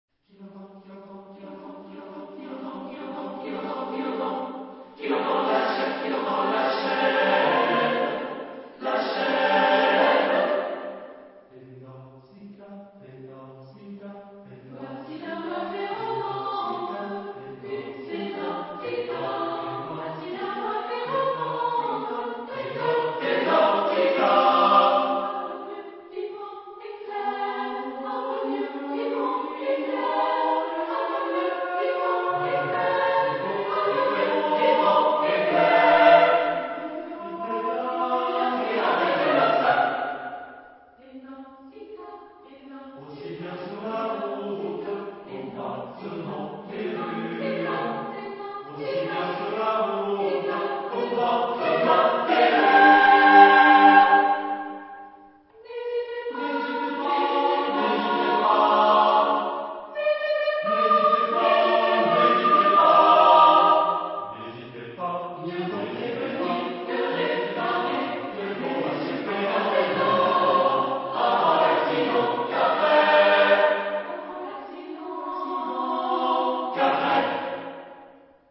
Genre-Style-Forme : contemporain ; Profane
Type de choeur : SATB  (4 voix mixtes )